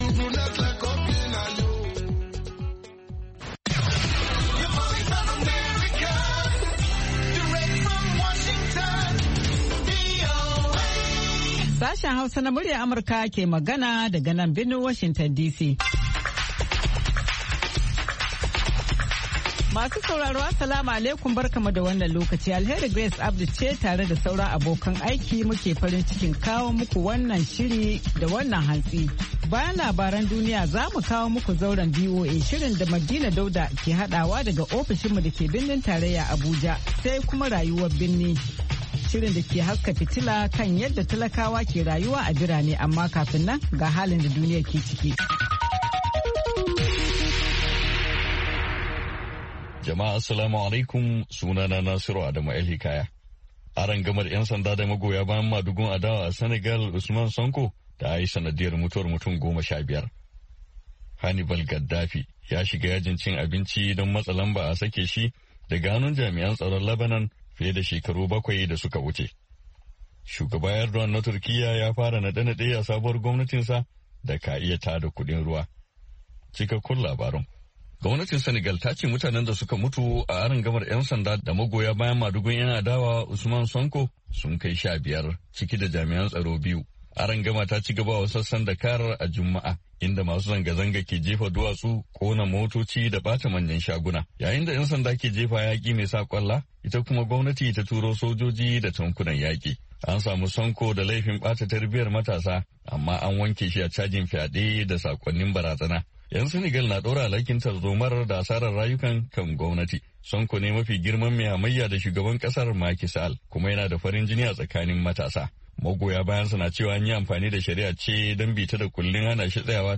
Mu kan komo da karfe 8 na safe agogon Najeriya da Nijar domin sake gabatar muku da labarai da hirarraki, da sharhin jaridu kama daga Najeriya zuwa Nijar har Ghana, da kuma ra’ayoyinku.